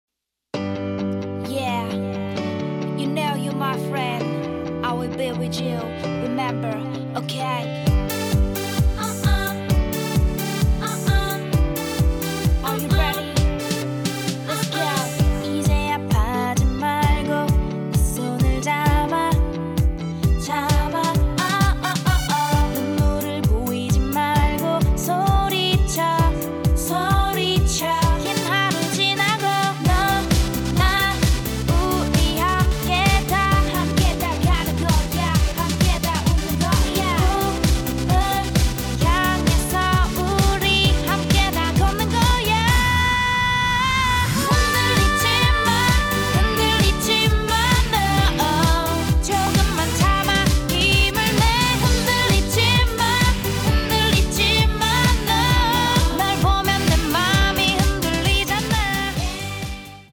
장르 : 댄스